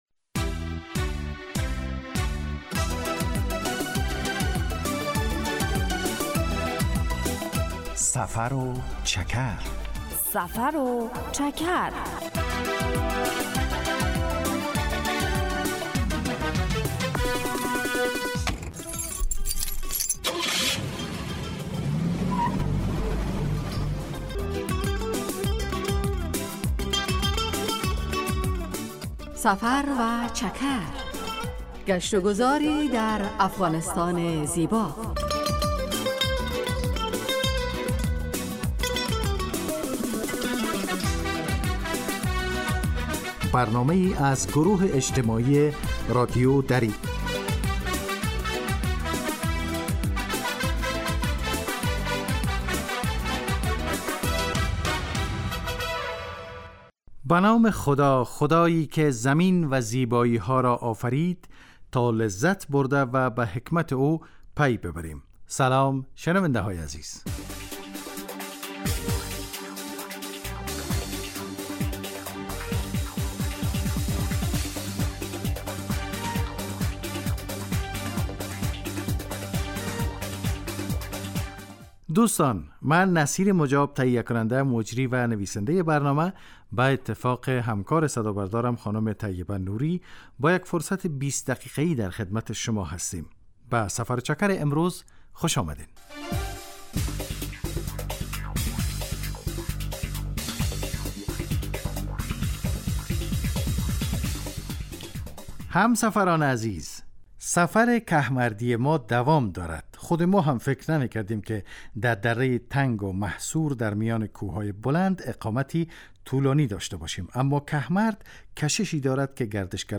هدف: آشنایی با فرهنگ عامه و جغرافیای شهری و روستایی افغانستان که معلومات مفید را در قالب گزارش و گفتگو های جالب و آهنگ های متناسب تقدیم می کند.